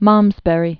(mämzbĕrē, -bə-rē, -brē) 1090?-1143?